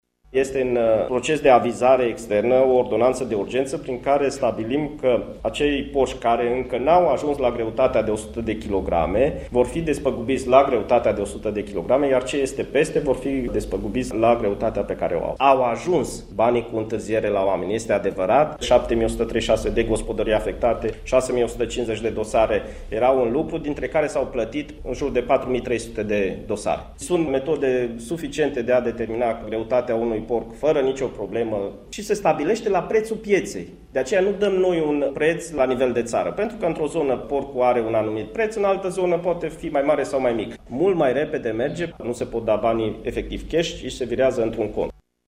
Oficialul guvernamental a precizat că Executivul acţionează pentru simplificarea modalităţii de despăgubire a proprietarilor de animale astfel încât banii să ajungă mai repede la fermieri: